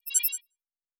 Sci-Fi Sounds / Interface / Error 04.wav
Error 04.wav